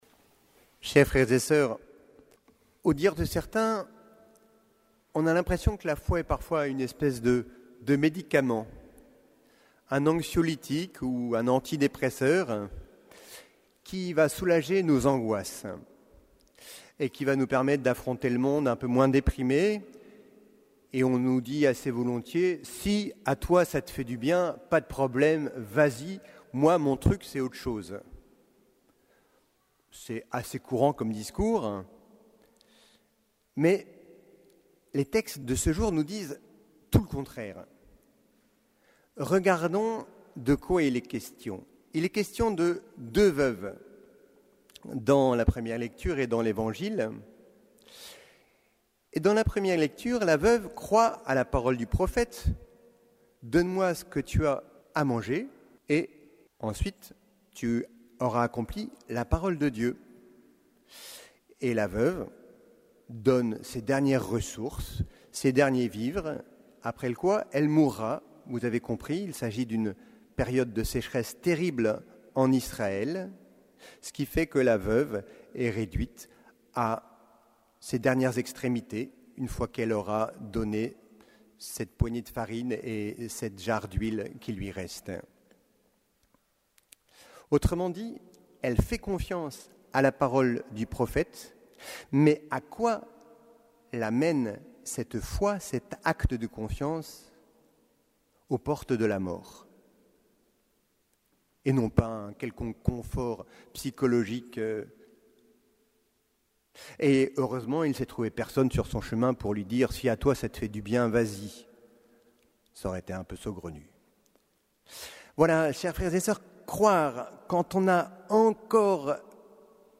Homélie du 32e dimanche du Temps Ordinaire